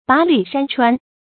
跋履山川 注音： ㄅㄚˊ ㄌㄩˇ ㄕㄢ ㄔㄨㄢ 讀音讀法： 意思解釋： 形容遠道奔波之苦。